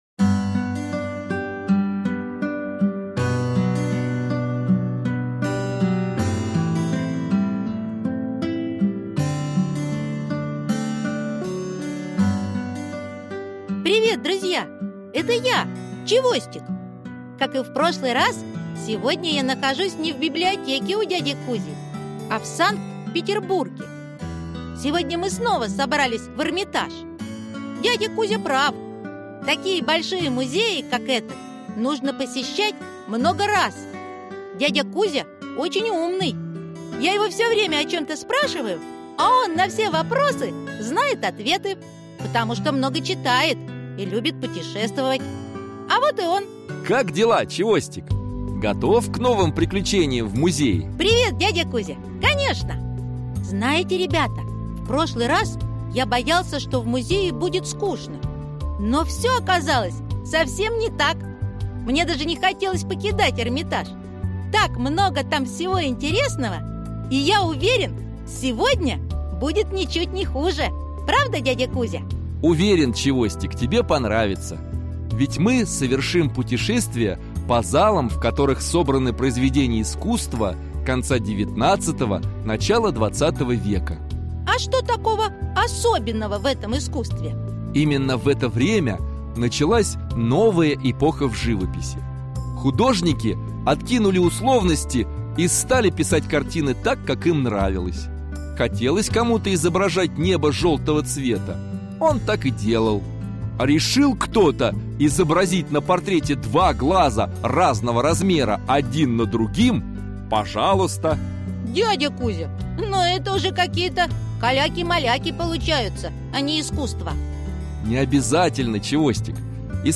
Аудиокнига Эрмитаж. Часть 3 | Библиотека аудиокниг